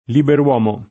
libero [l&bero] agg. — elis. nella locuz. lett. liber’uomo [